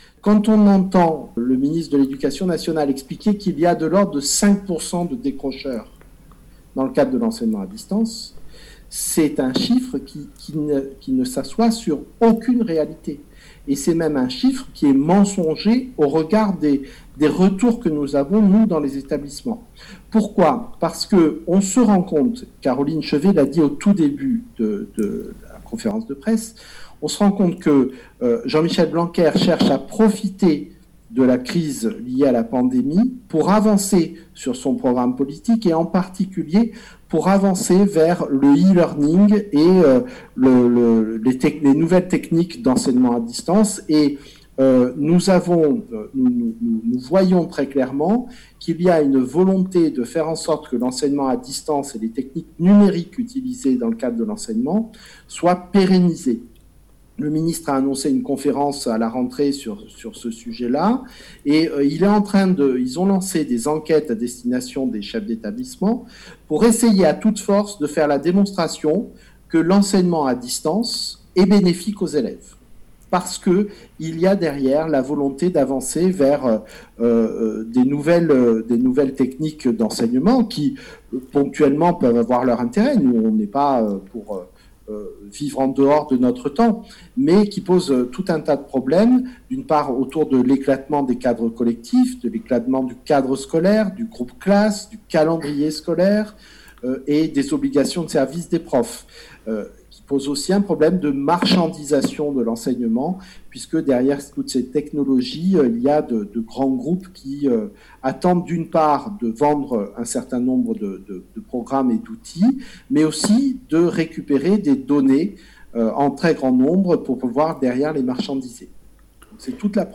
Entretiens.